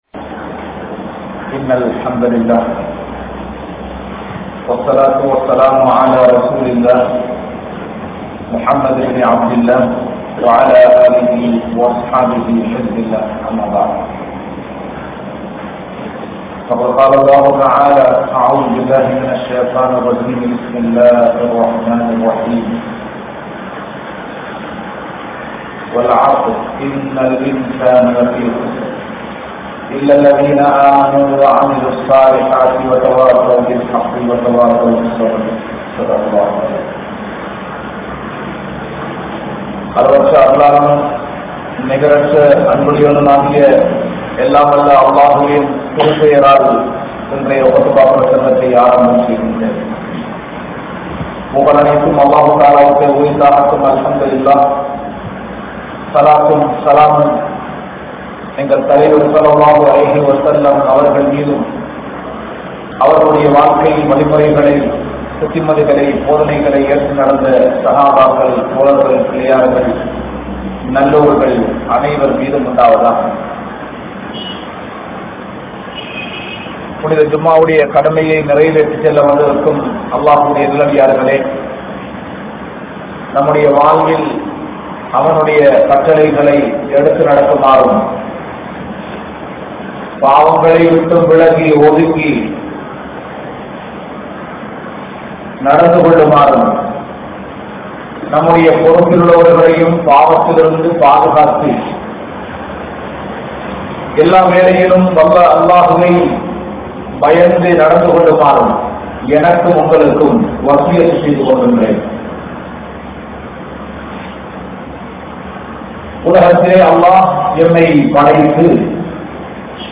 Mobile`um Indraya Vaalifarhalum (மொபைலும் இன்றைய வாலிபர்களும்) | Audio Bayans | All Ceylon Muslim Youth Community | Addalaichenai